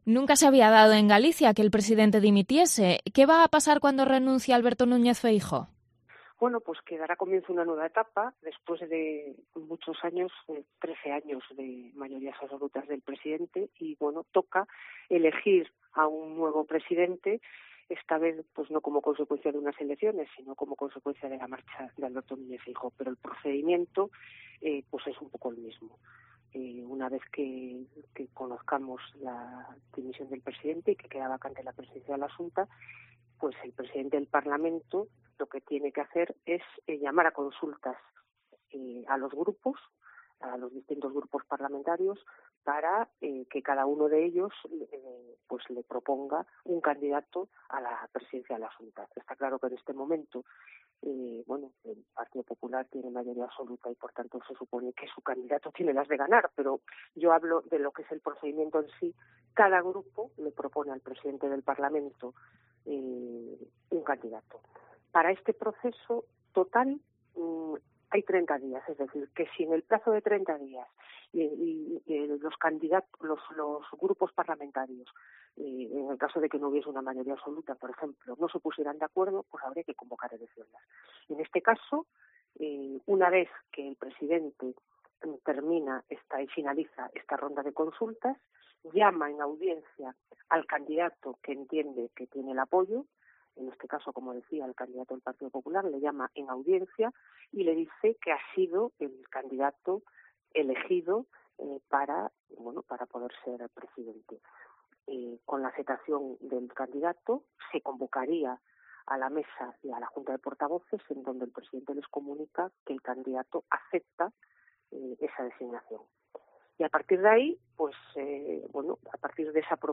La expresidenta del Parlamento de Galicia ha explicado en COPE Pontevedra los trámites para que Alfonso Rueda sea investido presidente
Entrevista a la expresidenta del Parlamento de Galicia, Pilar Rojo, sobre el relevo en la Xunta